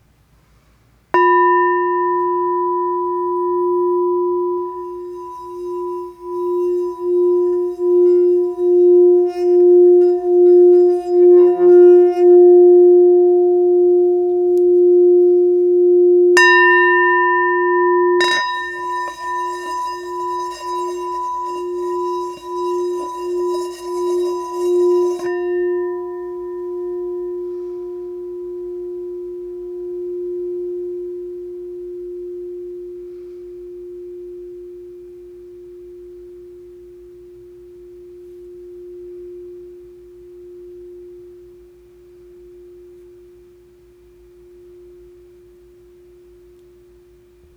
F Note 6.5″ Singing Bowl